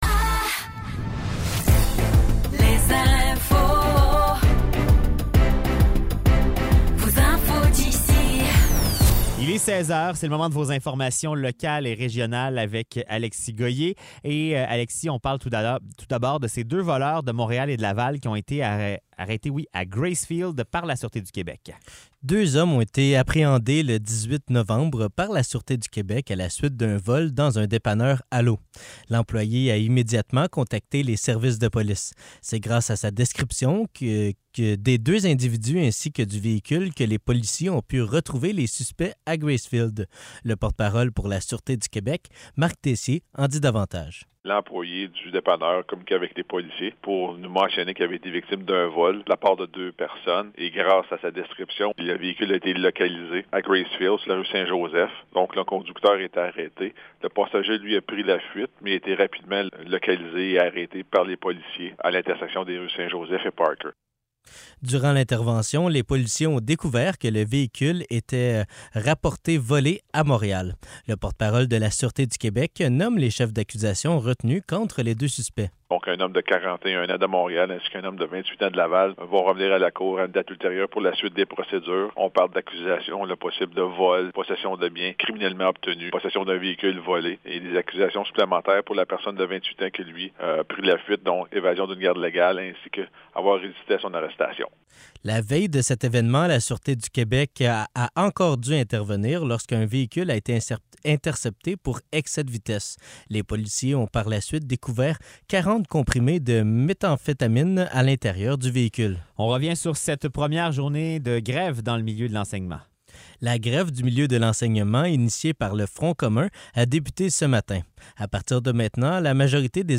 Nouvelles locales - 21 novembre 2023 - 16 h